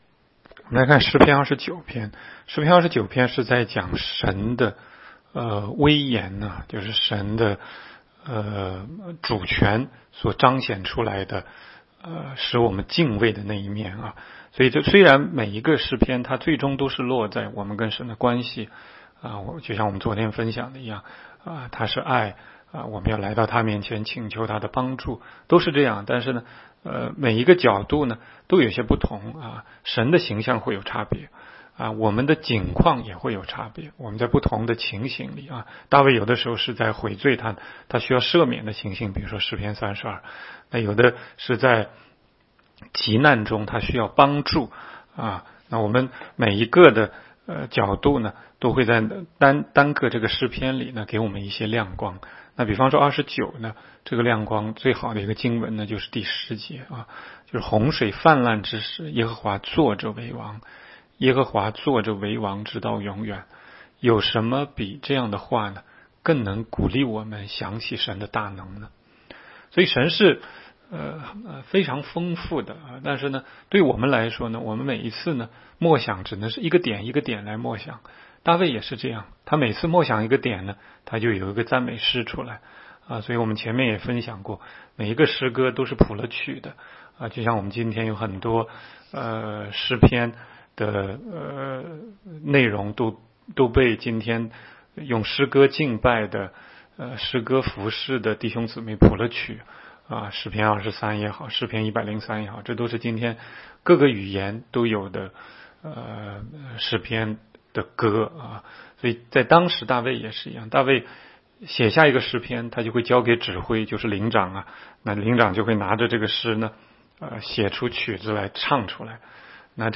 16街讲道录音 - 每日读经-《诗篇》29章